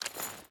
Water Chain Walk 2.ogg